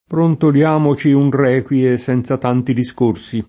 requie [ r $ k UL e ] s. f. — come nome di preghiera (adattam. it. del lat. requiem ), s. m. (inv.): Brontoliamoci un requie Senza tanti discorsi [ brontol L# mo © i un r $ k UL e S$ n Z a t # nti di S k 1 r S i ] (Giusti)